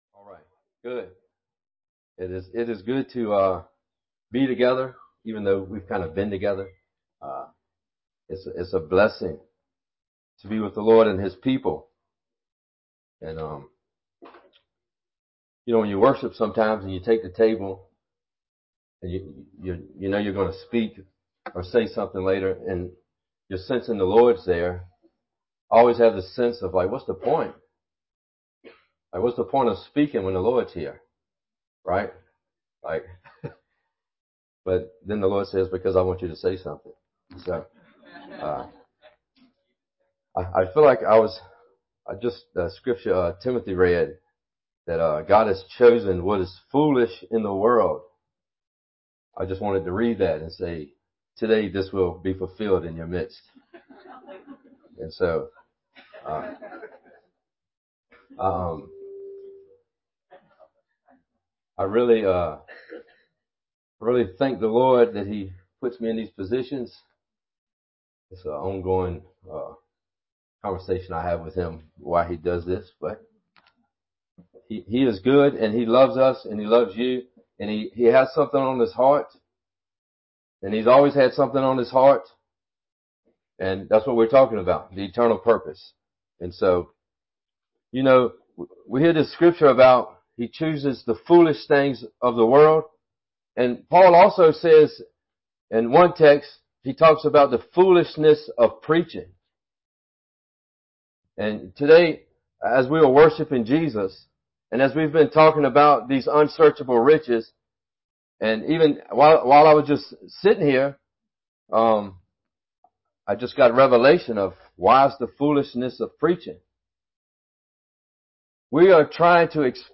Winter Youth Conference